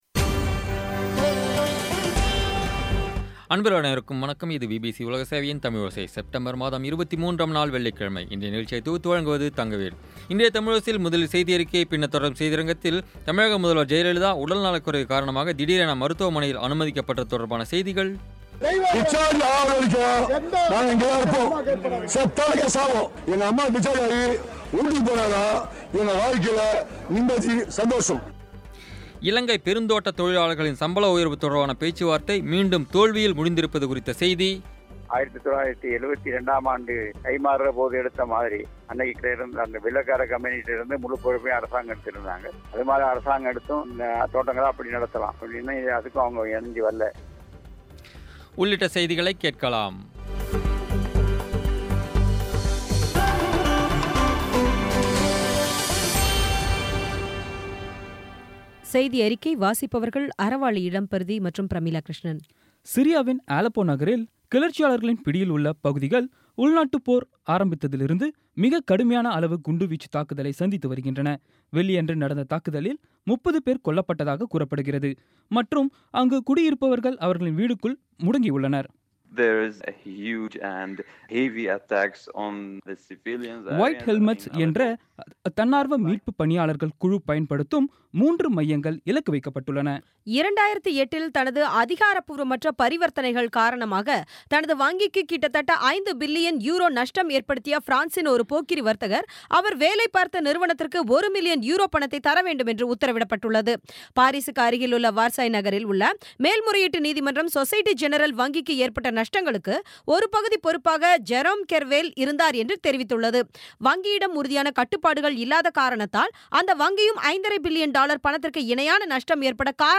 இன்றைய தமிழோசையில், முதலில் செய்தியறிக்கை, பின்னர் தொடரும் செய்தியரங்கத்தில், தமிழக முதல்வர் ஜெயலலிதா, உடல்நலக்குறைவு காரணமாக திடீரென மருத்துவமனையில் அனுமதிக்கப்பட்டது தொடர்பான செய்திகள் இலங்கை பெருந் தோட்டத் தொழிலாளர்களின் சம்பள உயர்வு தொடர்பான பேச்சுவார்த்தை மீண்டும் தோல்வியில் முடிந்திருப்பது குறித்த செய்தி உள்ளிட்ட செய்திகளைக் கேட்கலாம்.